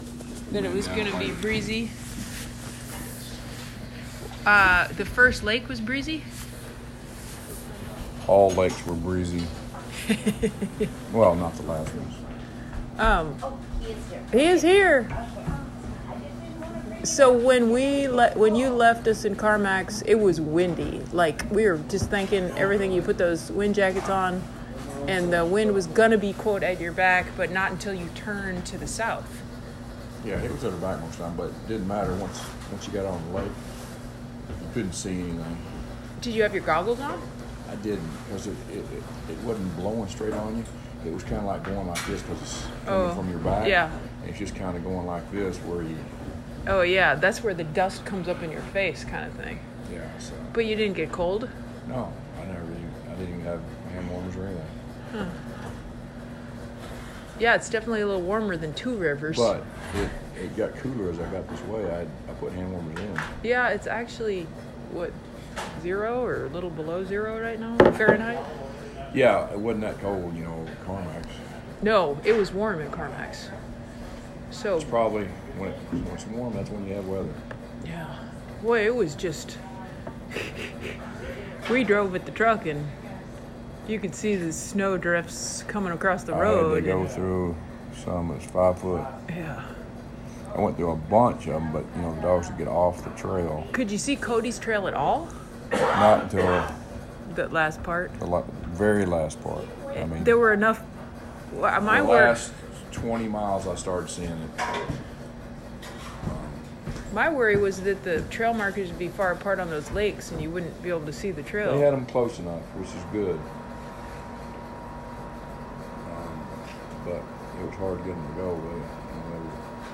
These verbal messages and interview-like sound recordings are so wonderful!!!